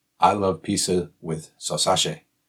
Q&A_12_response_w_accent.mp3